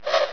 arrow.wav